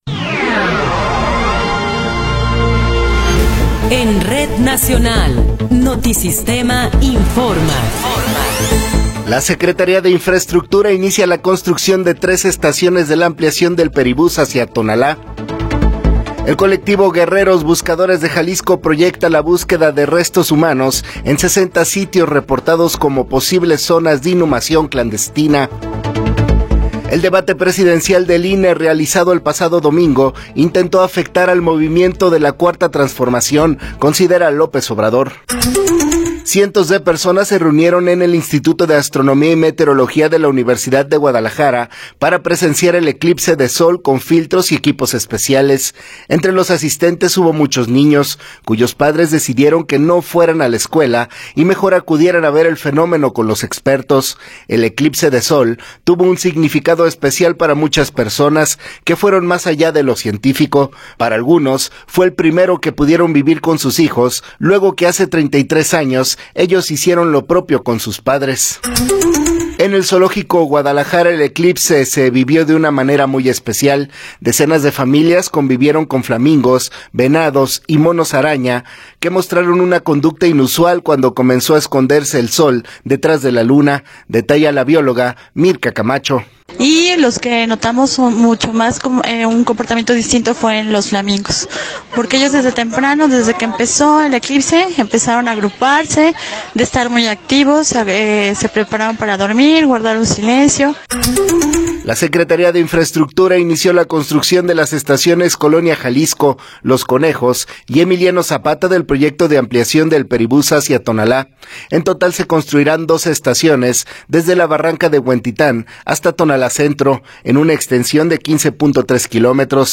Noticiero 9 hrs. – 9 de Abril de 2024
Resumen informativo Notisistema, la mejor y más completa información cada hora en la hora.